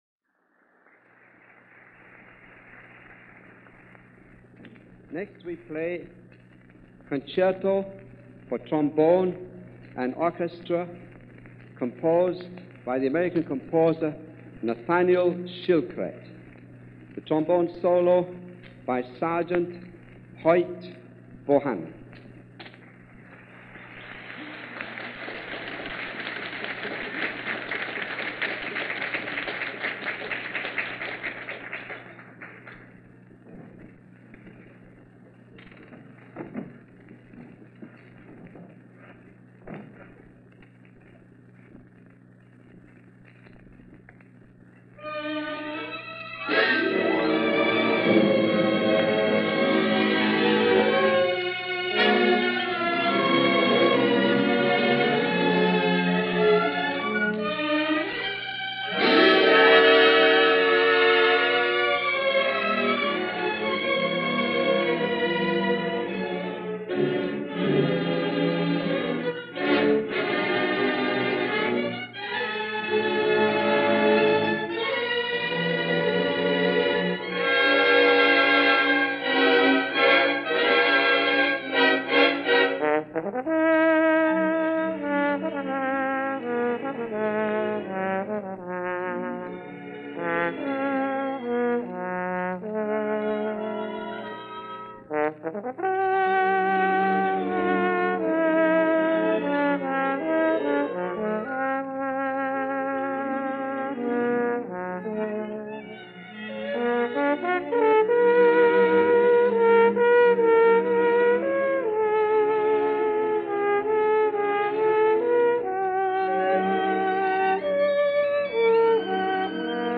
Hollywood Bowl
trombone solo
performing at the Hollywood Bowl on July 28, 1946.
Not the world premier, but the West Coast premier.